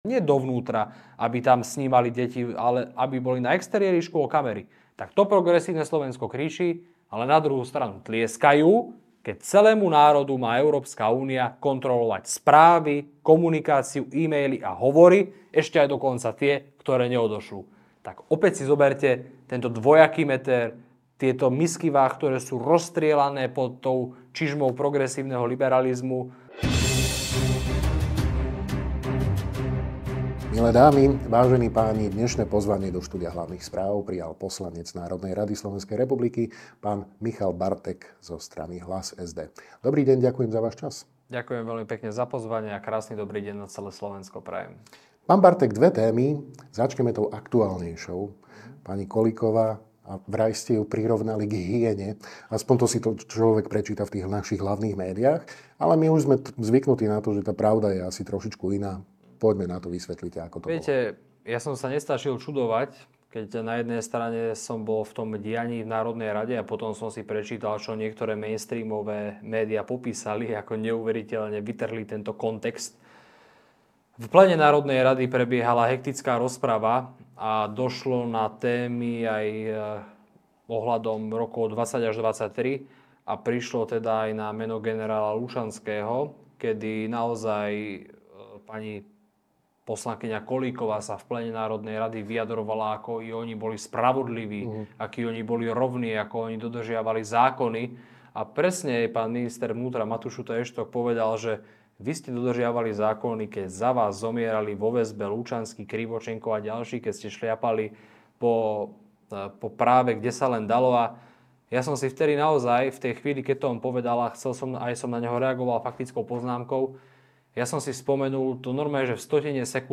Dozviete sa vo videorozhovore s poslancom NR SR za Hlas-SD, Michalom Bartekom.